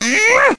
One of Mario's voice clips in Mario & Luigi: Superstar Saga
MarioAngryM&LSS.mp3